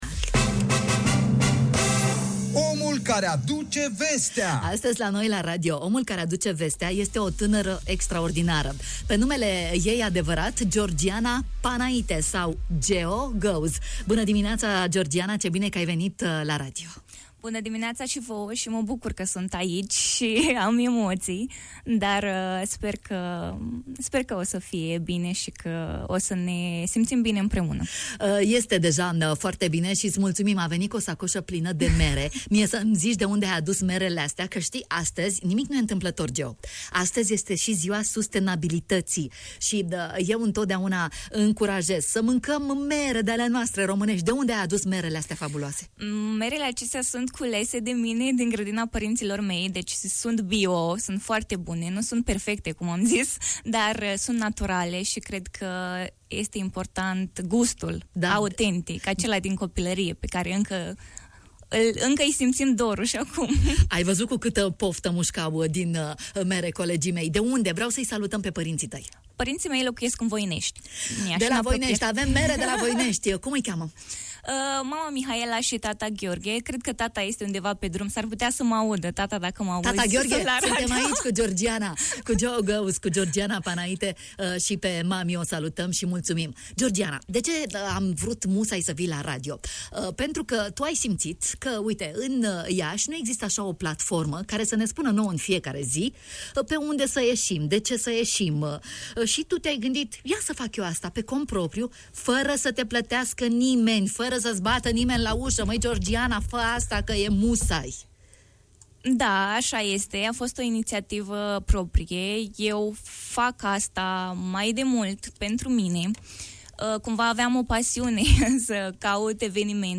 a fost în această dimineață la microfonul nostru.